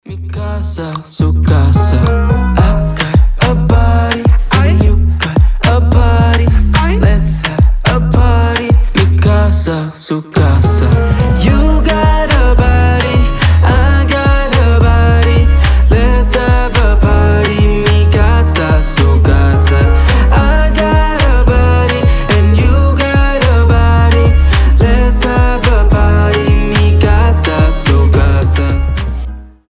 • Качество: 320, Stereo
мужской голос
ритмичные
заводные
реггетон